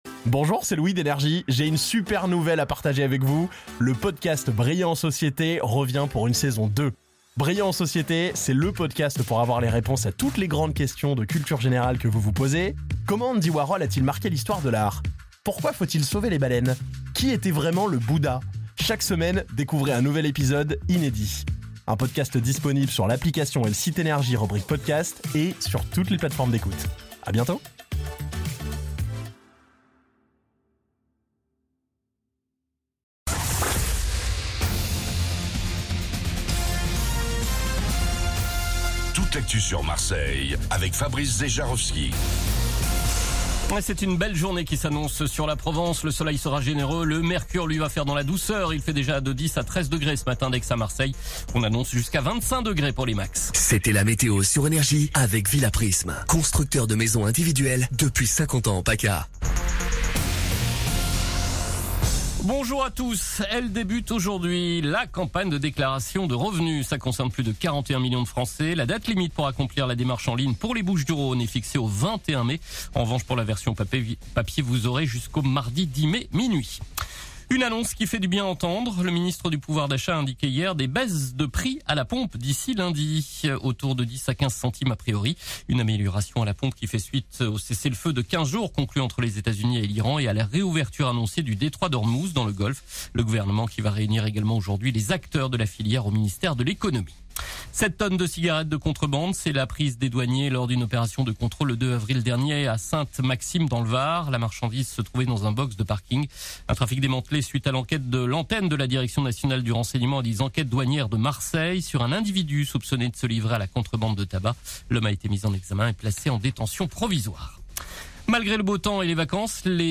Réécoutez vos INFOS, METEO et TRAFIC de NRJ MARSEILLE du jeudi 09 avril 2026 à 07h30